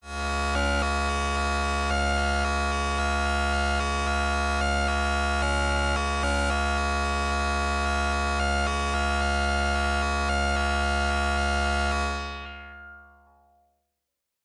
描述：通过Modular Sample从模拟合成器采样的单音。